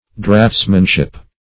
Search Result for " draughtsmanship" : The Collaborative International Dictionary of English v.0.48: Draughtsmanship \Draughts"man*ship\, n. The office, art, or work of a draughtsman.